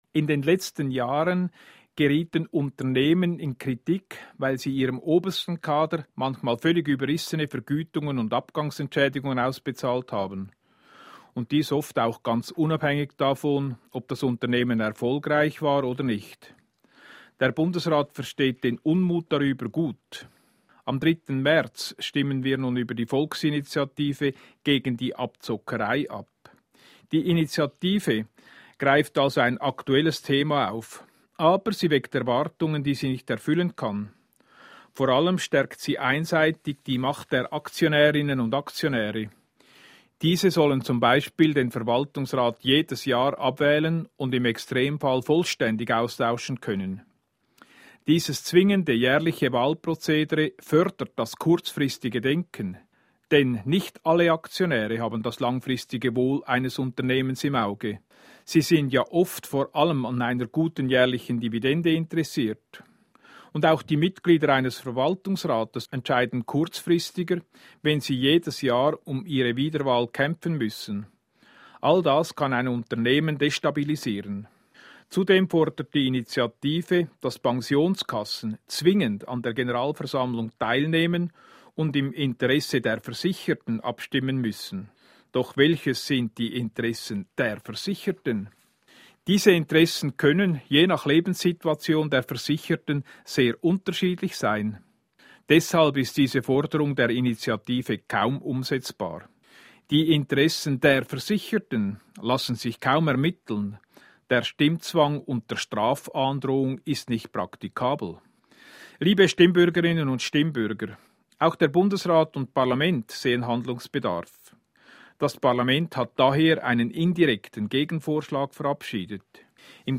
Bundesrat Ueli Maurer